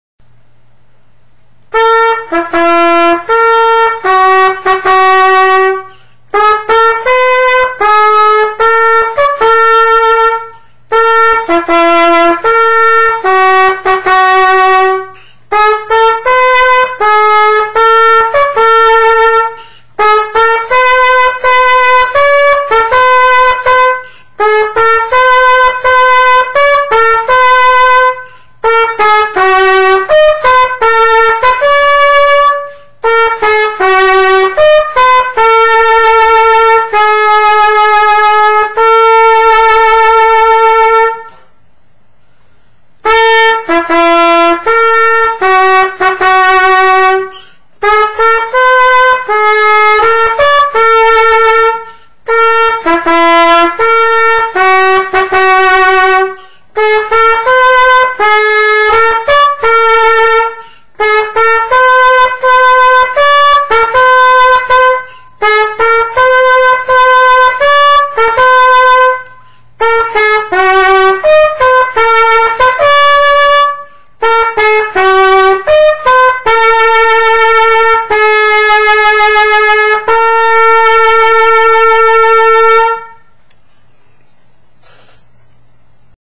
на трубе